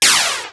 RetroGamesSoundFX
Shoot06.wav